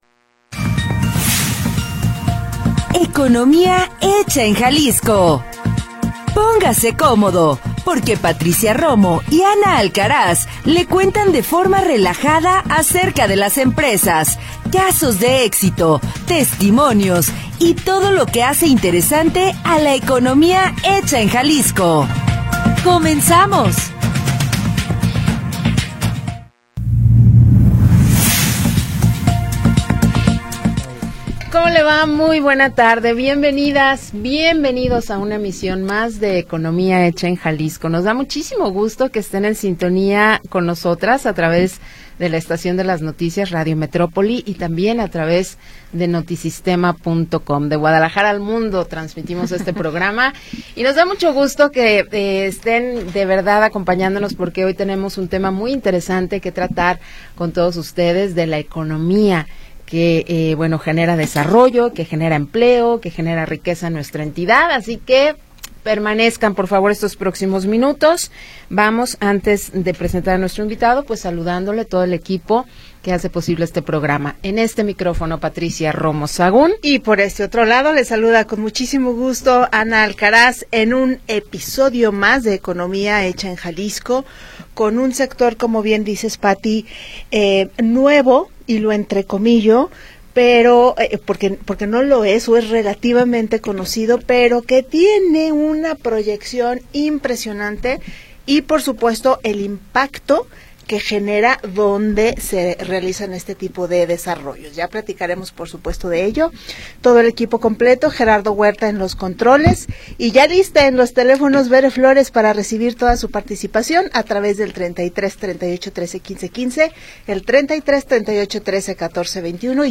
le cuentan de forma relajada acerca de las empresas, casos de éxito, testimonios y todo lo que hace interesante a la economía hecha en Jalisco. Programa transmitido el 18 de Junio de 2025.